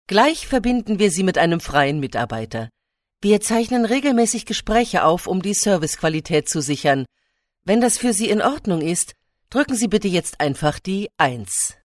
Radio Spot - Blumen Baumann Ostern - Antenne Kaiserslautern